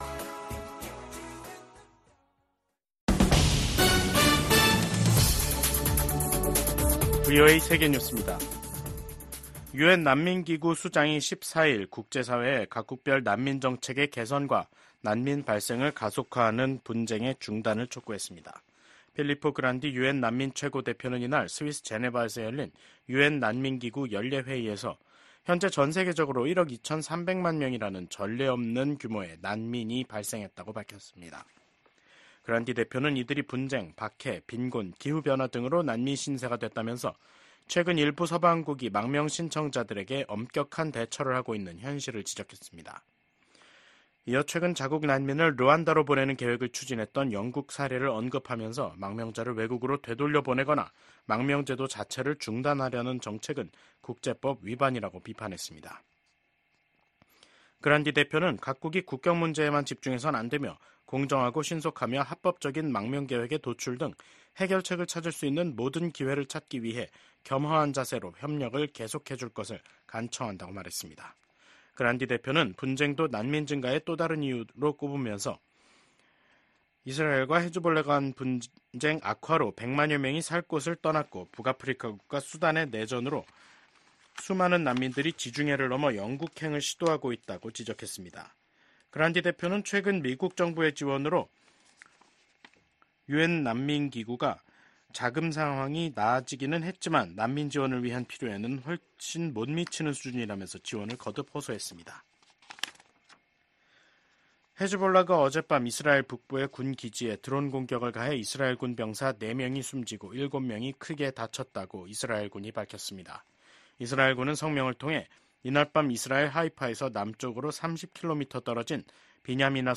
VOA 한국어 간판 뉴스 프로그램 '뉴스 투데이', 2024년 10월 14일 3부 방송입니다. 북한이 한국 측 무인기의 평양 침투를 주장하면서 한국과의 접경 부근 포병 부대들에게 사격 준비 태세를 지시했습니다. 미국 북한인권특사는 북한에서 공개재판과 공개처형이 늘어나는 등 북한 인권 실태가 더욱 열악해지고 있다고 지적했습니다.